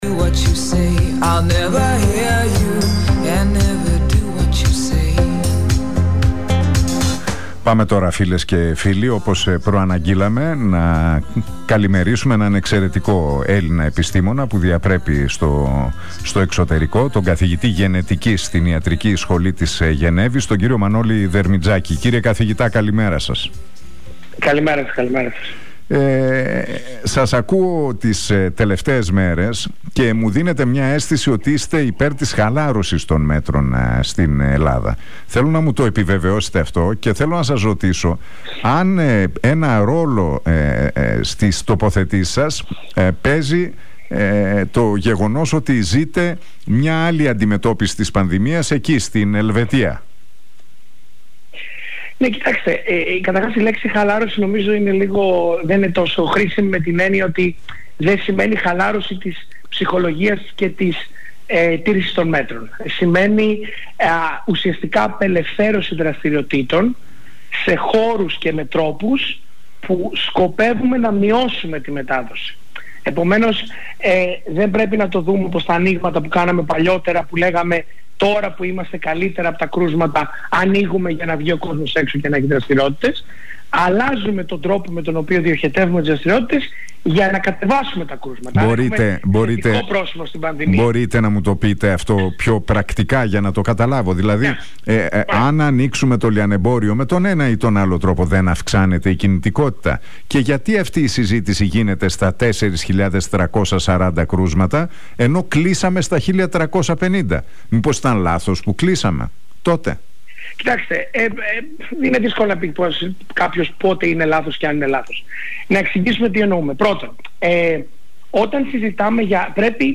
Ο καθηγητής Γενετικής στην Ιατρική Σχολή του Πανεπιστημίου της Γενεύης, Μανώλης Δερμιτζάκης, μιλώντας στον Realfm 97,8...